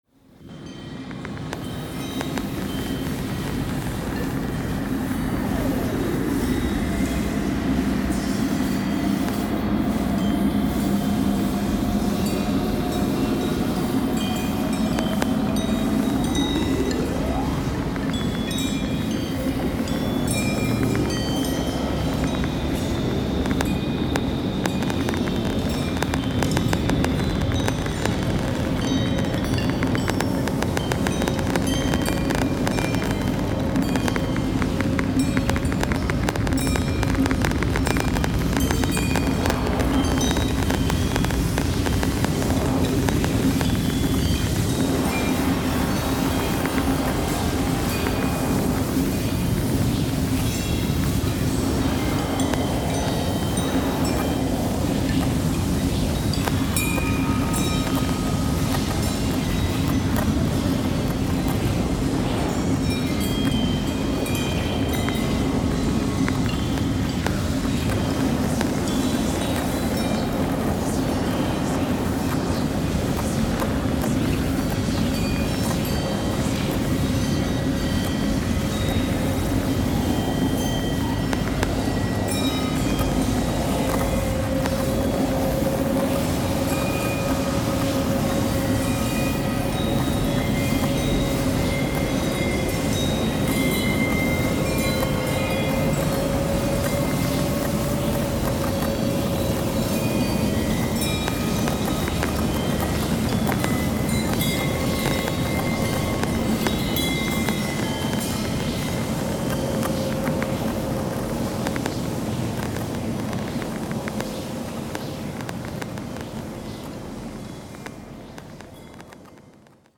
Genre: sound installation.